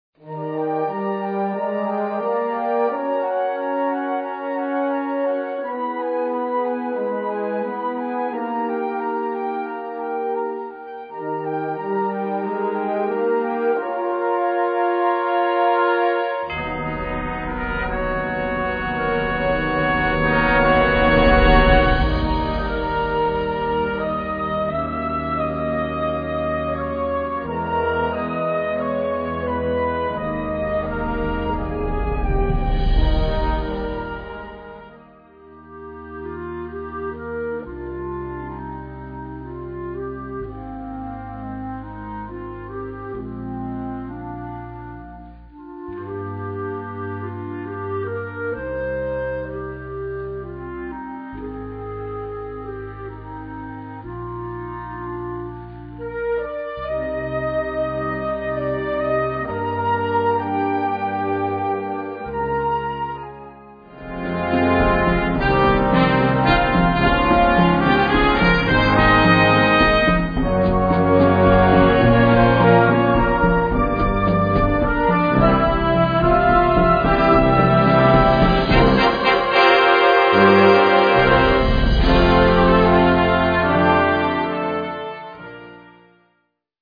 5:00 Minuten Besetzung: Blasorchester Tonprobe